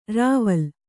♪ rāval